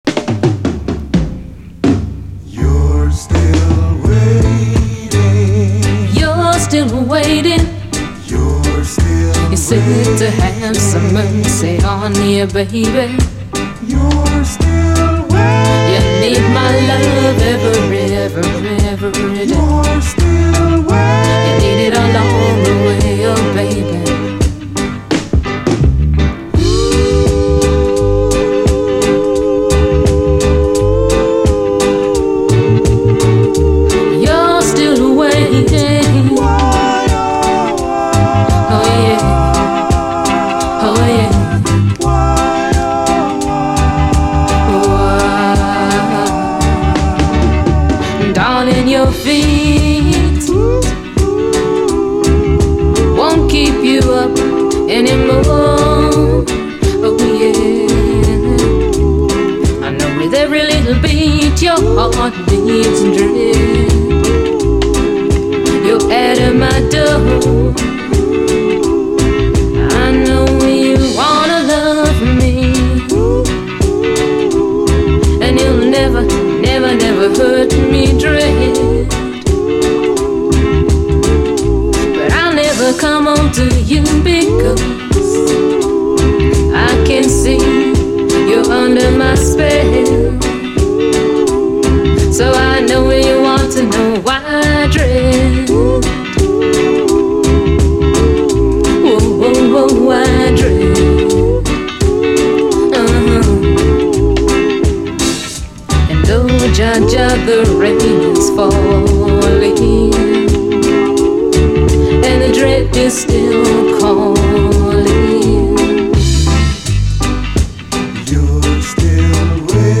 盤細かいスレ多く見た目VGですが実際のノイズは多くなくプレイVG++程度